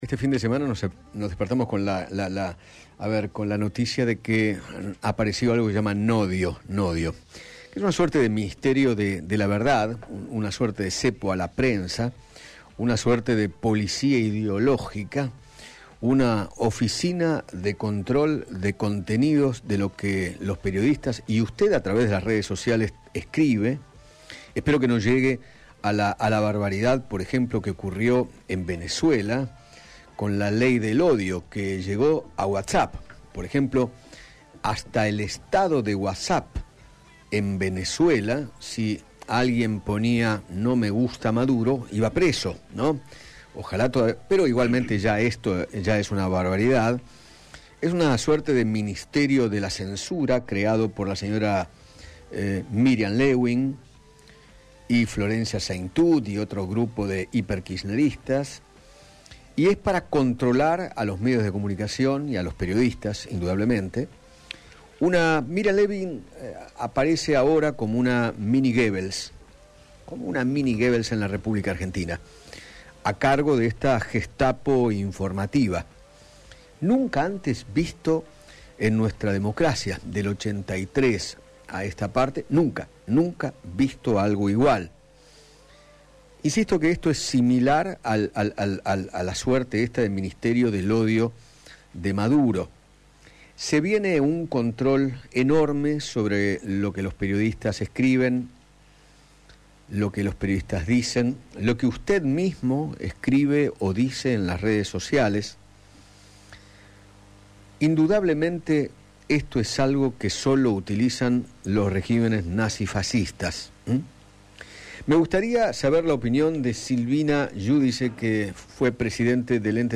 Silvina Giudici, ex presidenta del Ente Nacional de Comunicaciones, dialogó con Eduardo Feinmann sobre la creación del NODIO, el organismo de vigilancia de los medios de comunicación, y sostuvo que “el oficialismo entiende que la opinión en internet debe ser regulada”.